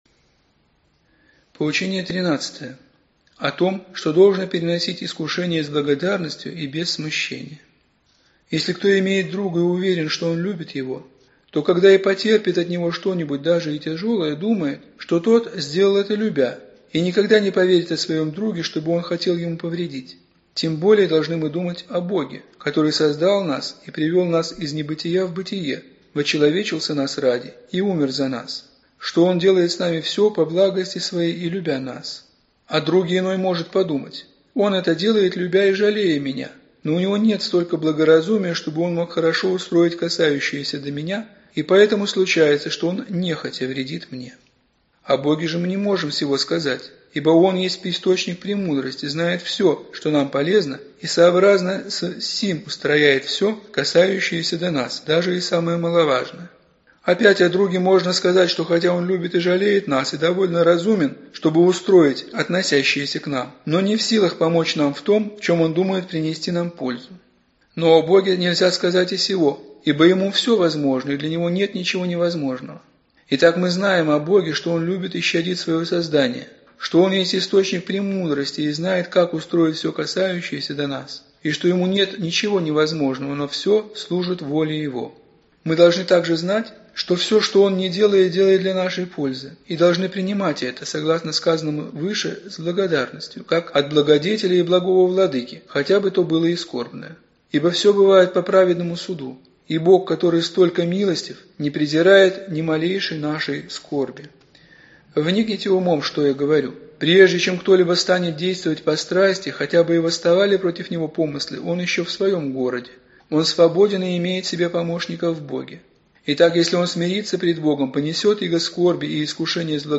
Аудиокнига Душеполезные поучения аввы Дорофея. Избранное | Библиотека аудиокниг